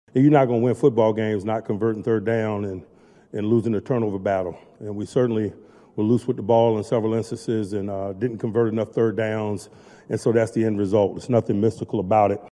Tomlin said that the offense struggled all night.